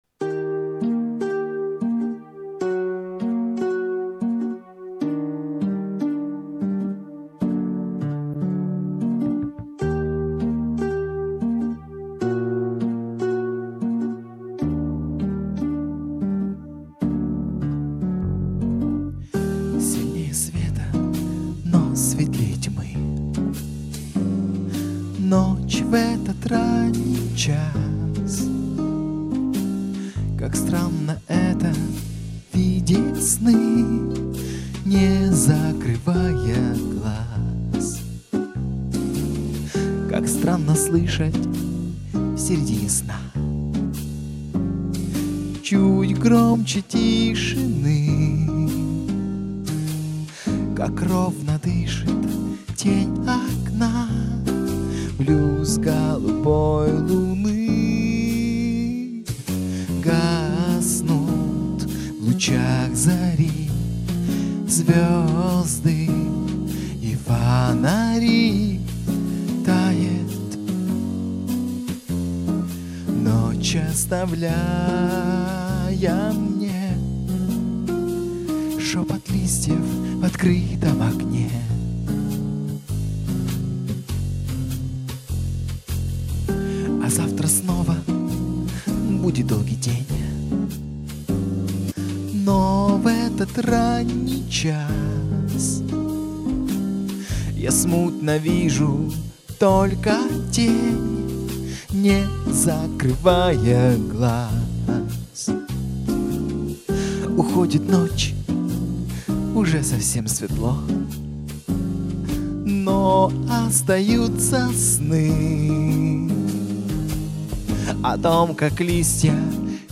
Blues-2009.mp3